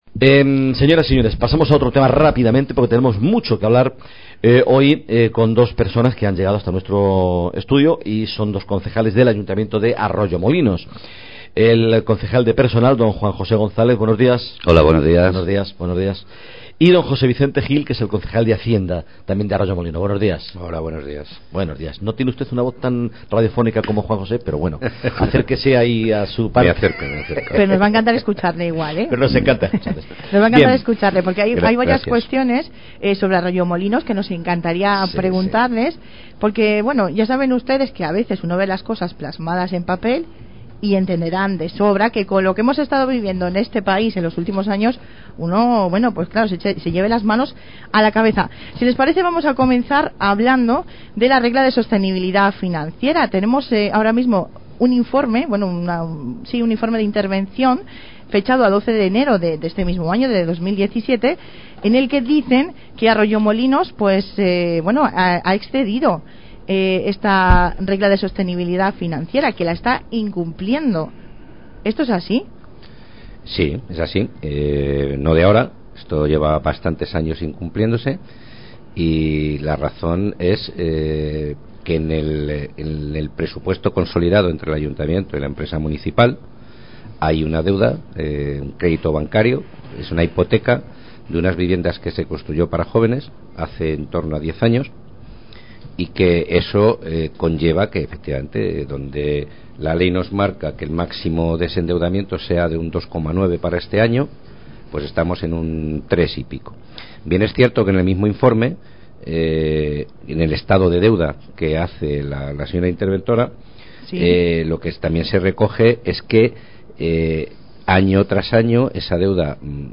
Entrevista a los Concejales de Personal y Hacienda sobre Presupuestos 2017 y RPT (Cope Madrid Sur)
Entrevista realizada el 1 de marzo de 2017 en Cope Madrid Sur a los Concejales de Personal, D. Juan José González, y de Hacienda, D. José Vicente Gil, del Ayuntamiento de Arroyomolinos, sobre la nueva RPT (Relación de Puestos de Trabajo) y los Presupuestos Generales 2017.